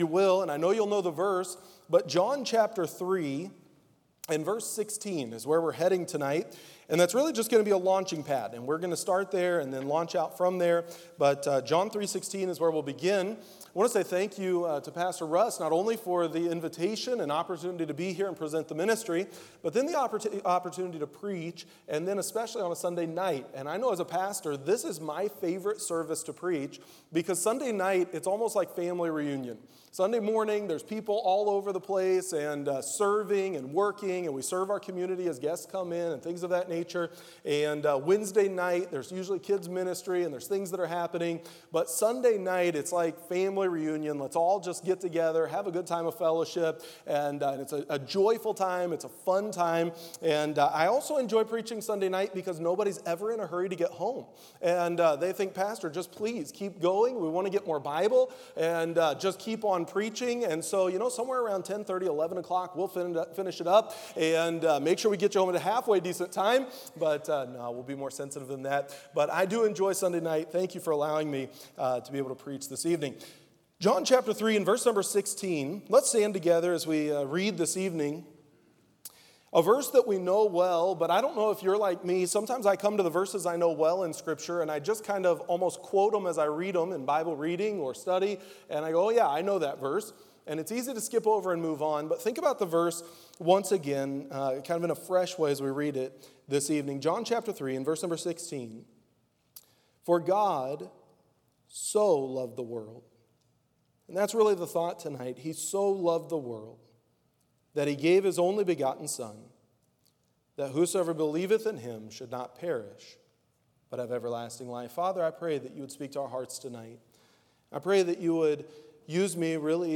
October 2022 Missions Conference Sunday Evening Scripture: John 3:16 Download: Audio